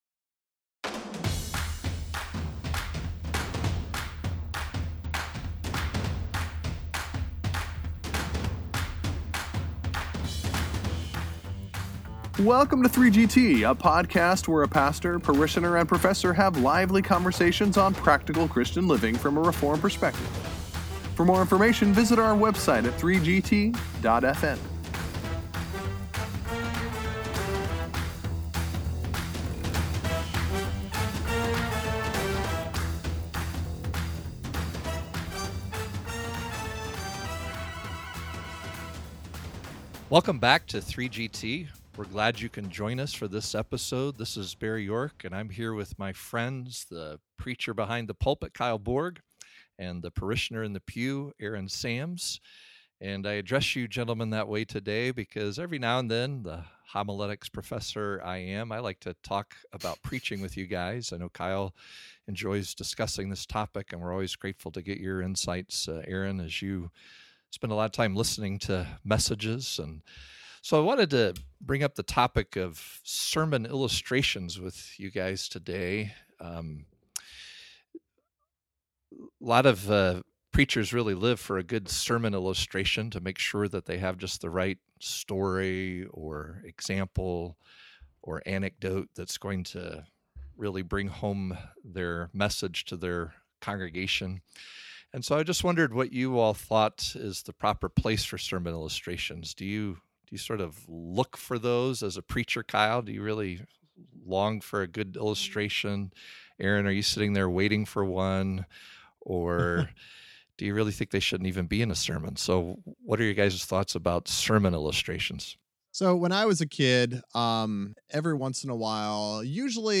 The three guys enjoy a discussion on the place of illustrations in a sermon.